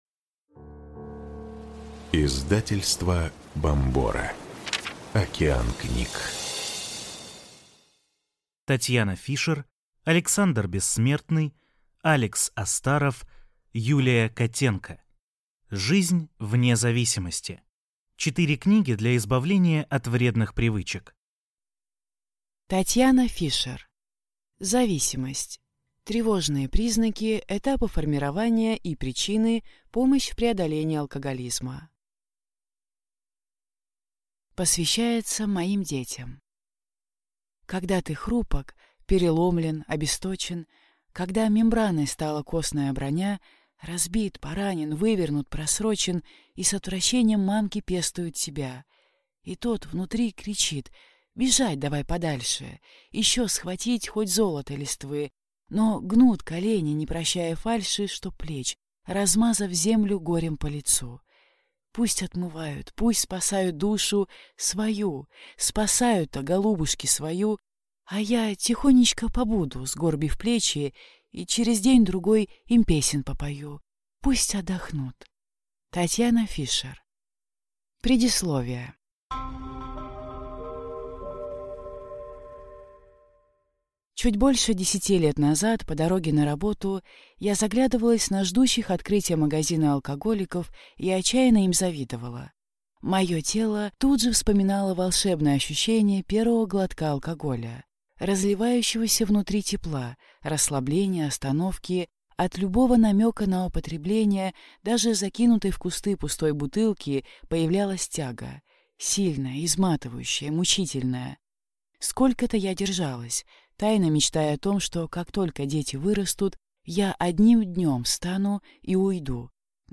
Слушать аудиокнигу Оценщик.